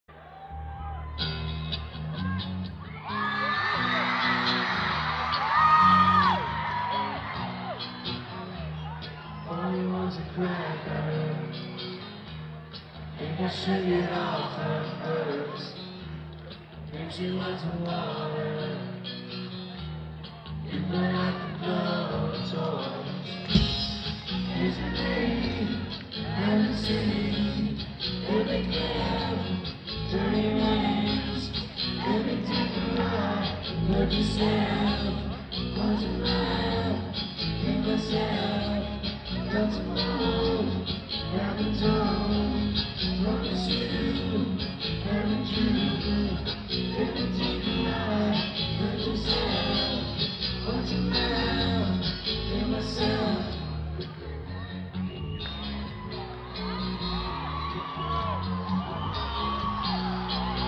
Рок
был записан на хорошей студии и за хорошие деньги